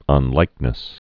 (ŭn-līknĭs)